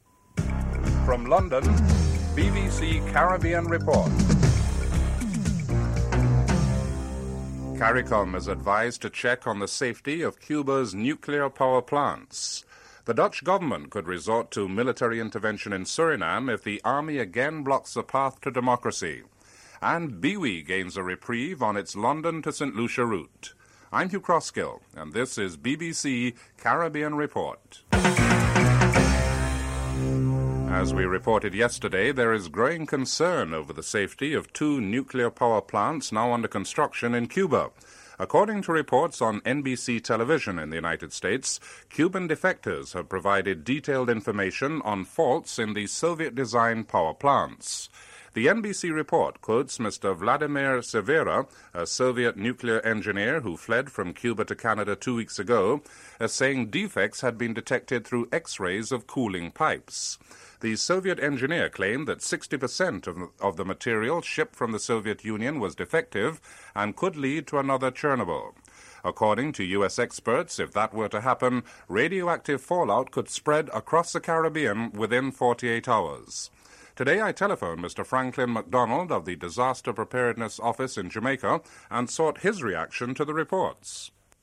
dc.descriptionUnrelated clip preludes the report.en_US
dc.description.tableofcontents5. The selection of the English cricket team is announced for the upcoming test match against the West Indies. Jonathan Agnew reports (13:20-14:35)en_US
dc.typeRecording, oralen_US